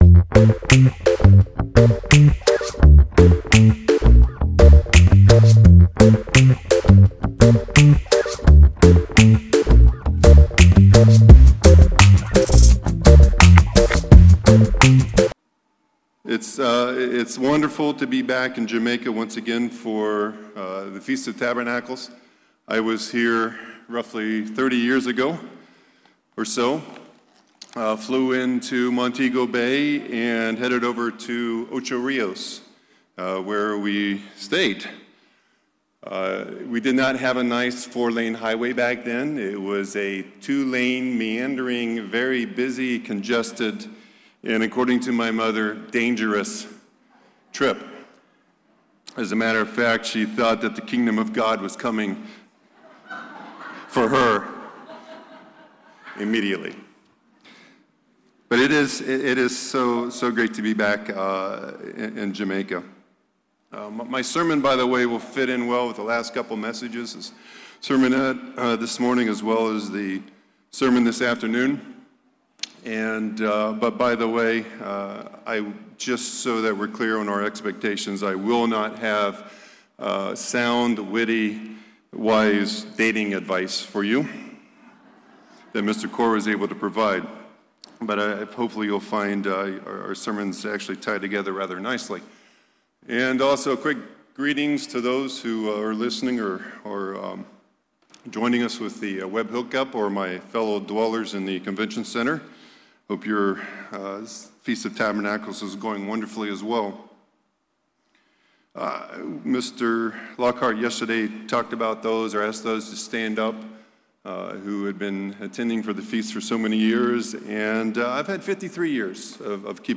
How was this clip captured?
This sermon was given at the Montego Bay, Jamaica 2021 Feast site.